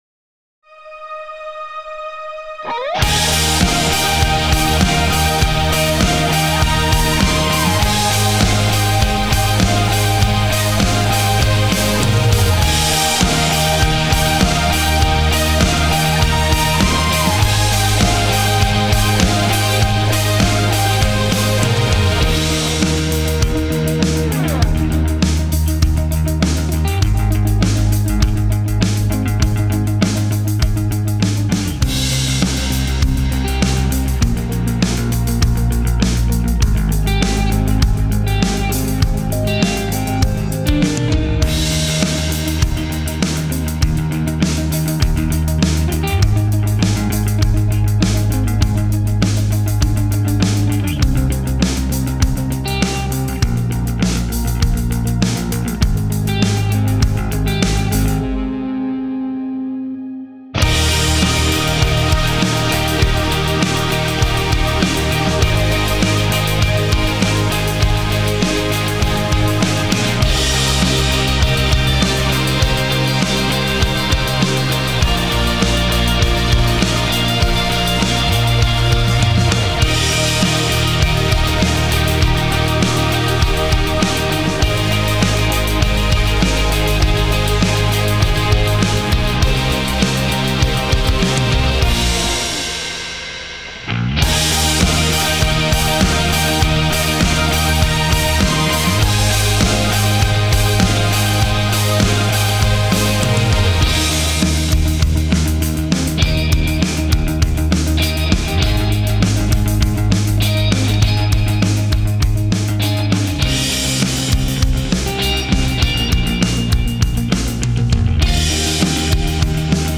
Key: E BPM: 100 Time sig: 4/4 Duration:  Size: 10.6MB
Contemporary Pop Worship